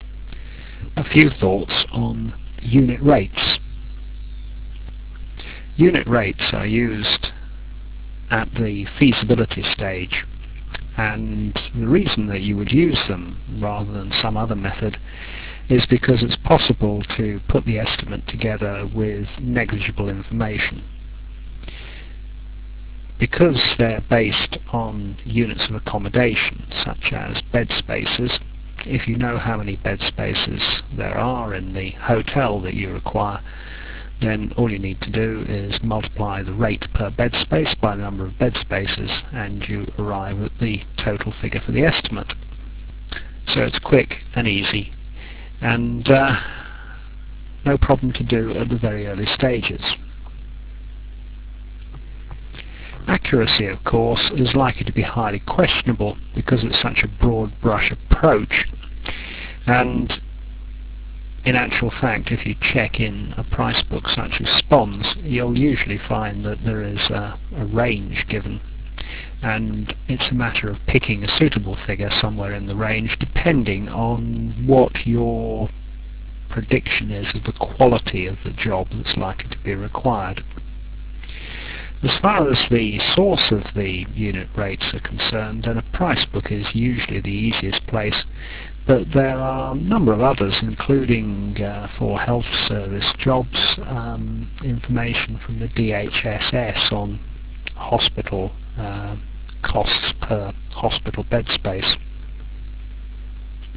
The sound quality is adequate but low fi.
Enjoy your lectures.........